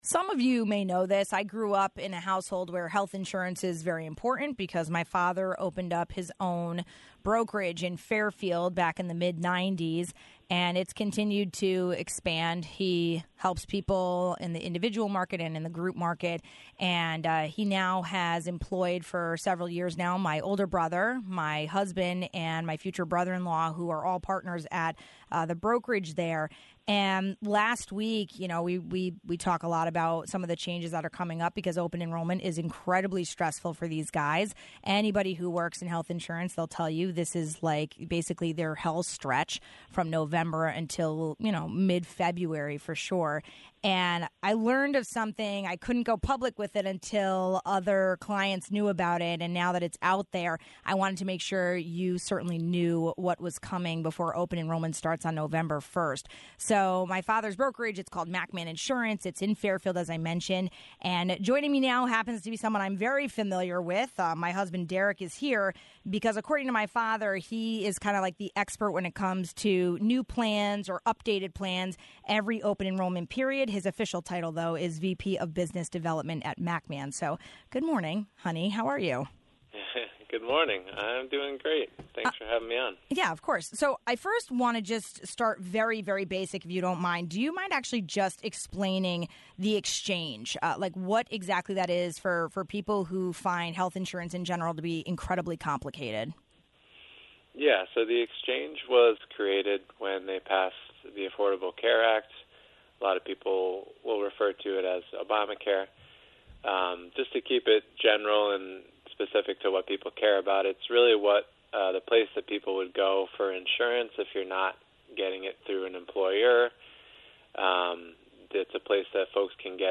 A bomb shell announcement was just dropped just weeks ahead of open enrollment for health insurance. We spoke with a local broker about the changes with Connecticare and what it means for thousands of residents.